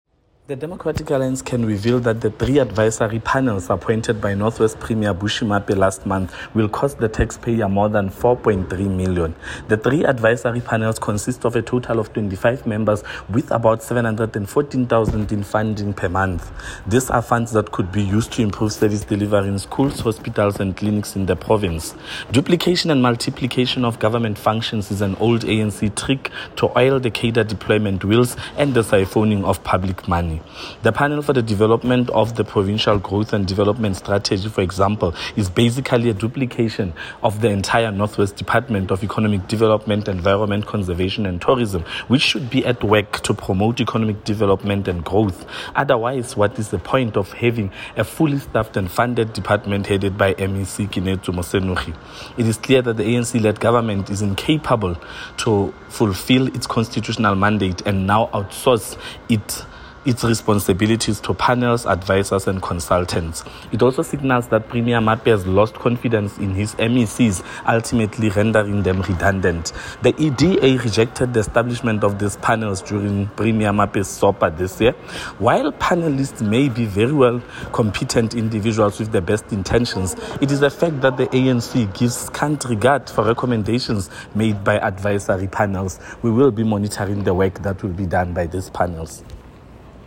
Note to Editors: Please find the attached soundbite in
English by Freddy Sonakile MPL.
FREDDY-SONAKILE-PANELS-ENGLISH-online-audio-converter.com_.mp3